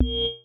BellishAccept6.wav